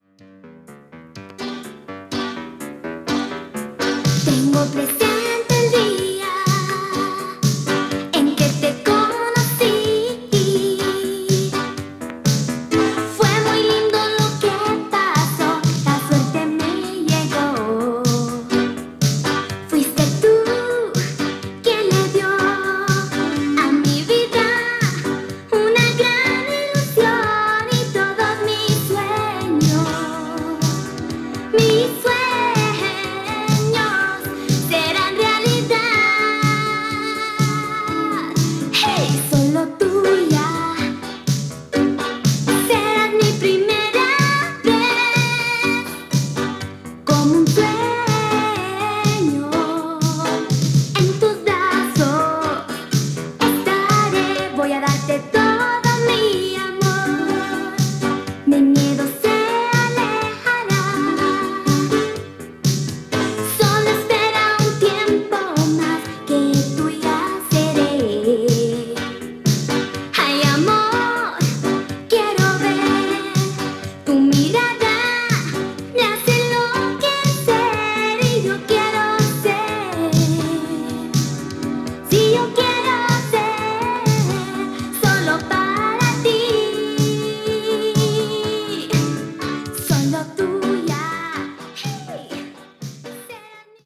(細かな擦れ、大きな擦りありますので試聴でご確認下さい)